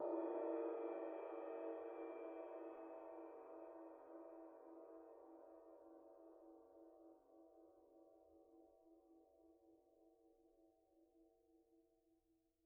Percussion
susCymb1-hit_pp_rr2.wav